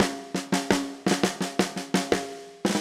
Index of /musicradar/80s-heat-samples/85bpm
AM_MiliSnareA_85-01.wav